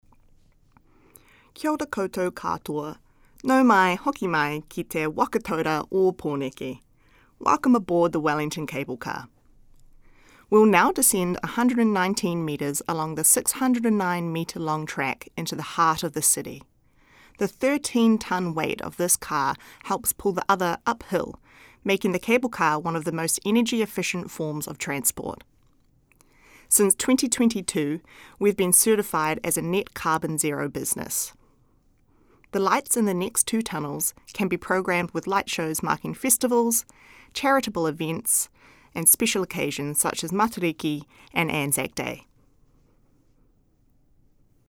Audio Commentary
Departing from Kelburn.